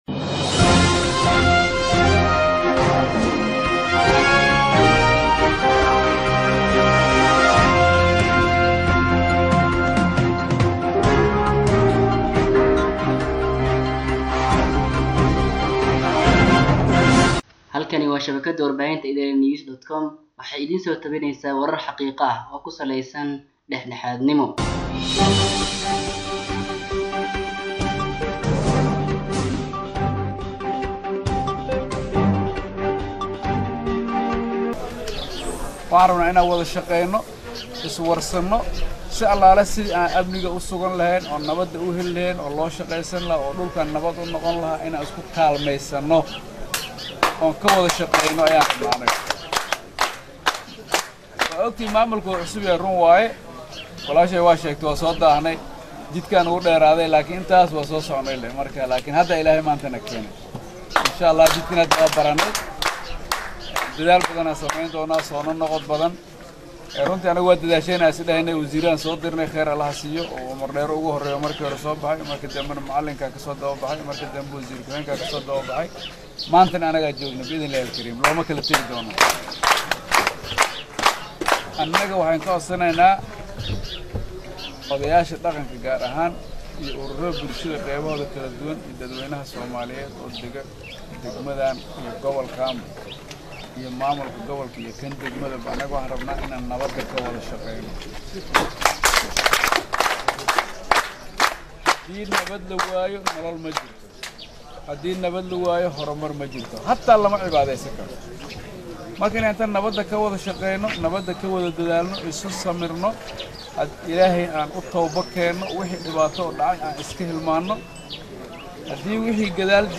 Dhageyso:Nuxurka Hadalkii Madaxweyne Shariif Xasan Uu U Jeediyey Odayaasha Dhaqanka Ee Wanleweyn Ee Gobolka Sh/Hoose
Halkan Ka Dhageyso Hadalkii Madaxweyne Shariif Xasan Sheekh Aadan Oo Dhameystiran.